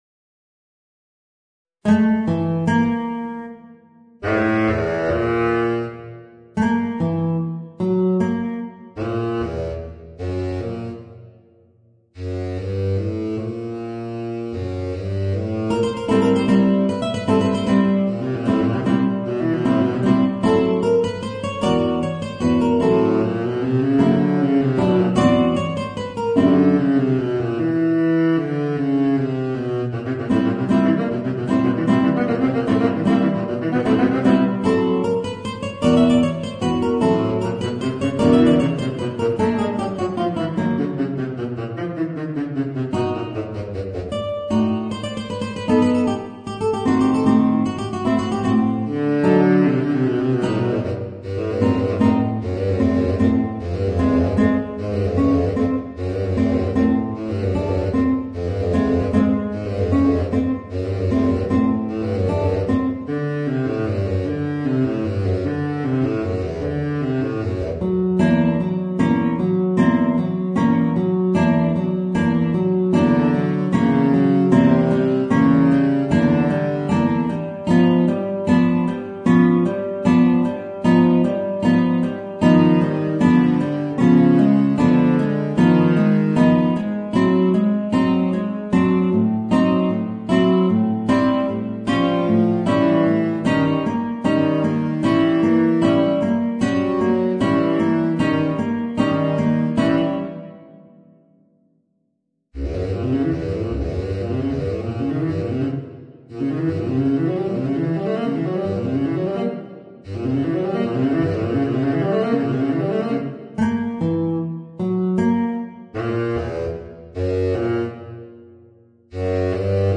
Voicing: Baritone Saxophone and Guitar